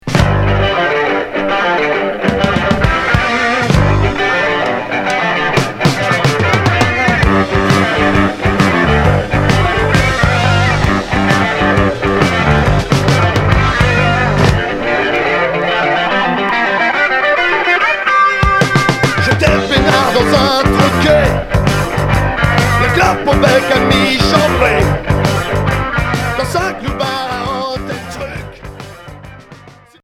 Heavy prog rock Unique 45t retour à l'accueil